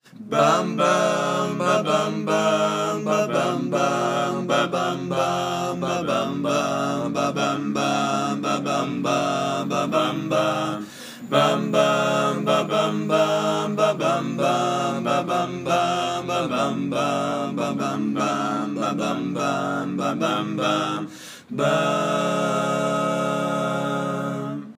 A capella, le retour
Il faut bien avouer que nous prenons toujours un plaisir fou à reprendre des airs à la seule force de nos voix.